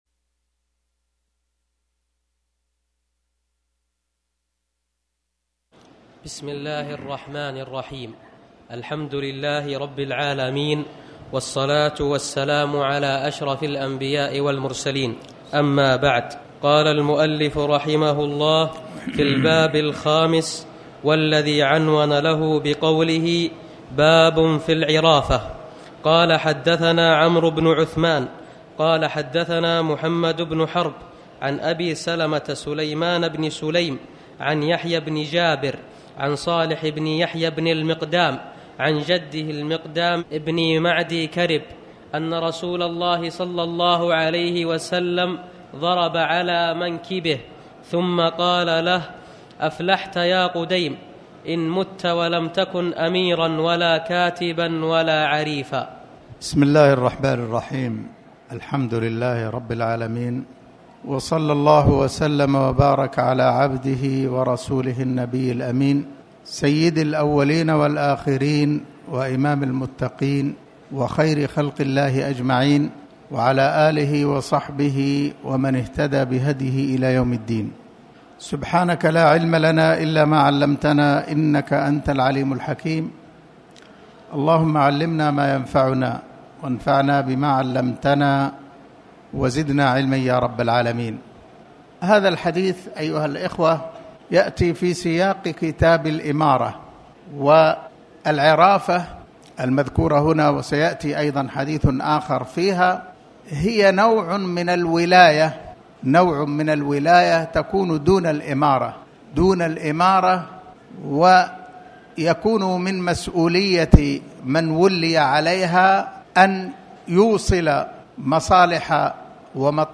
تاريخ النشر ١٦ شعبان ١٤٣٩ هـ المكان: المسجد الحرام الشيخ